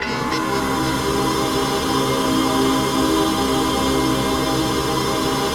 ATMOPAD07.wav